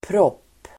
Uttal: [pråp:]